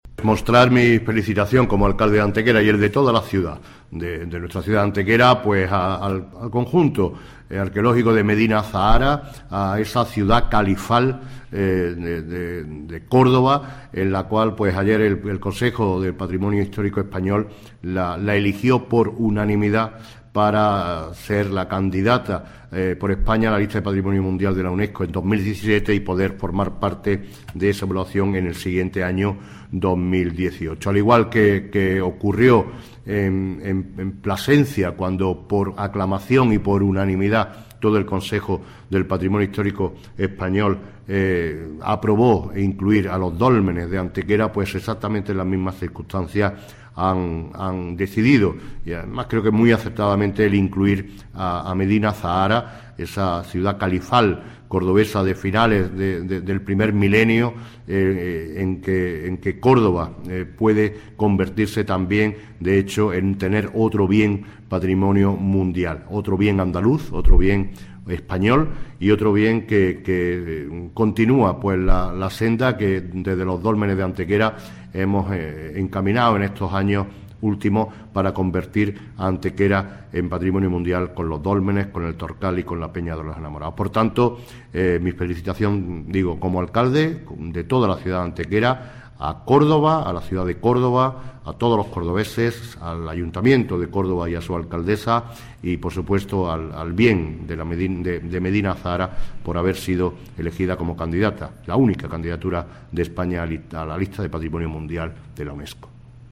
Cortes de voz M. Barón 832.47 kb Formato: mp3